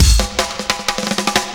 Drum_Roll.wav